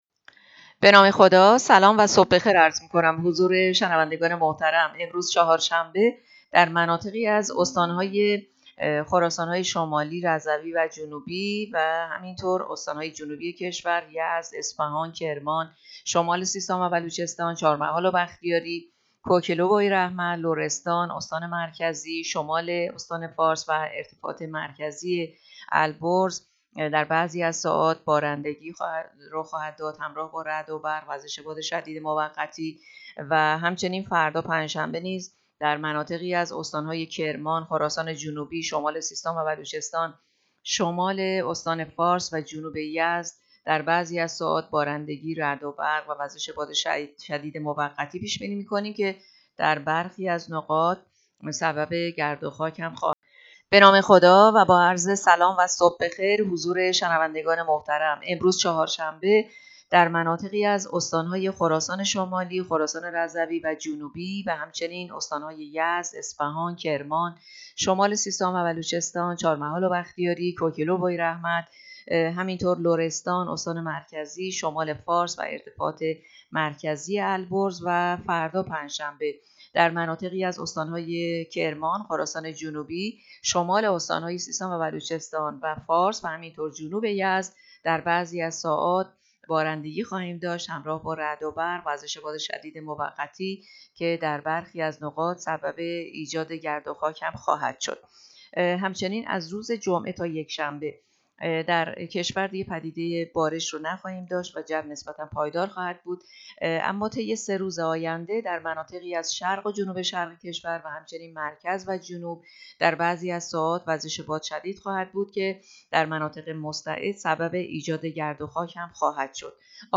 گزارش رادیو اینترنتی پایگاه‌ خبری از آخرین وضعیت آب‌وهوای ۲۲ اسفند؛